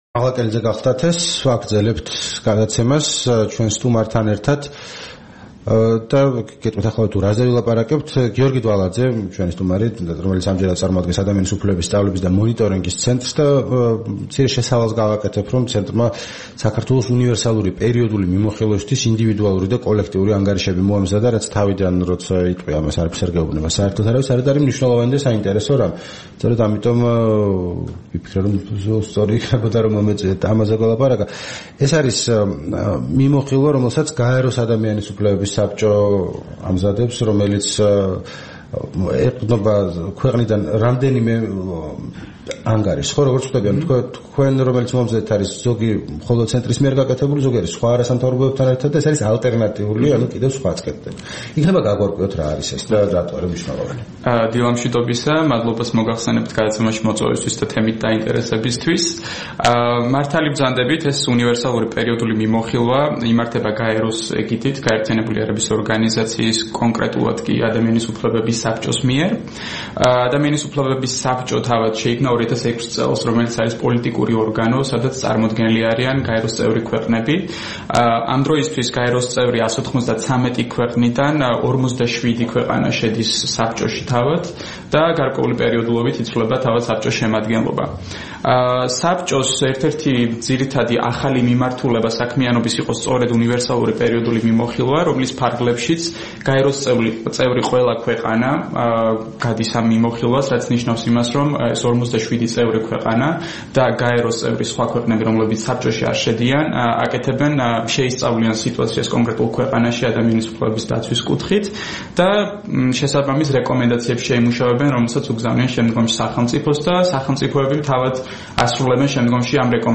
რადიო თავისუფლების თბილისის სტუდიაში სტუმრად იყო